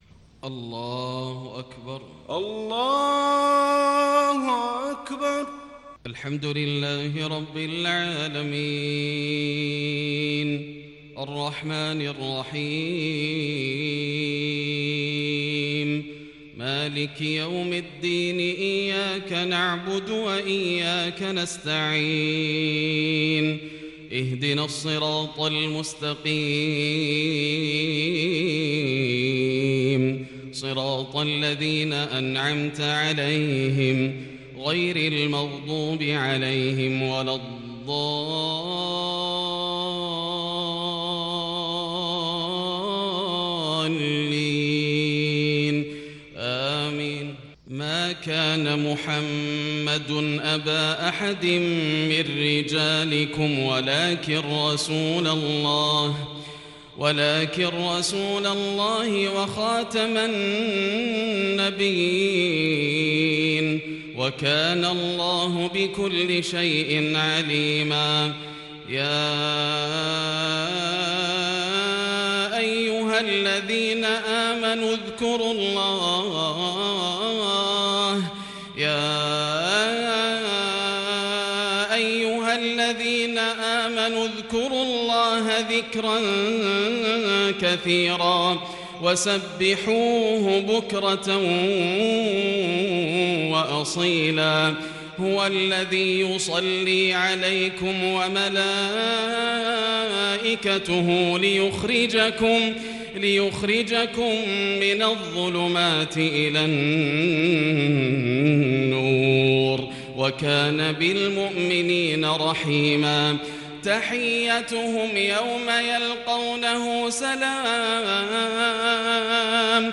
صلاة المغرب للشيخ ياسر الدوسري 19 ربيع الأول 1442 هـ
تِلَاوَات الْحَرَمَيْن .